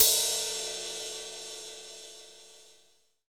Index of /90_sSampleCDs/Roland - Rhythm Section/CYM_FX Cymbals 1/CYM_Cymbal FX